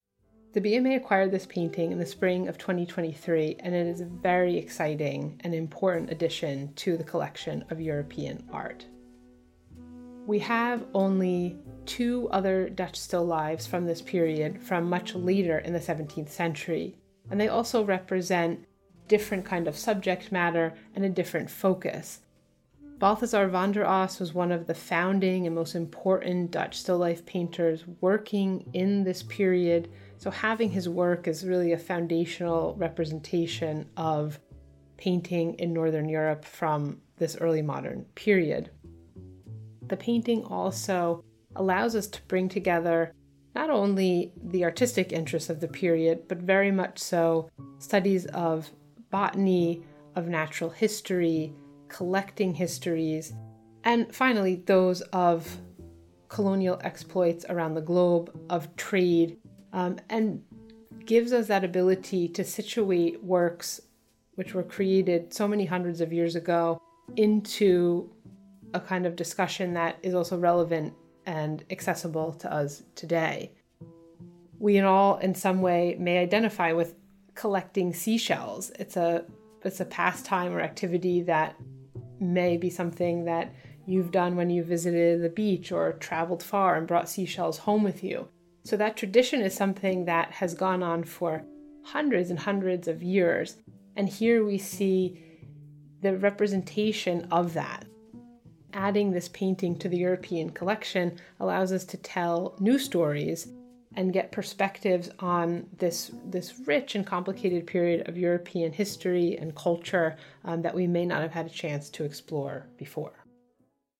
[jazz music softly playing]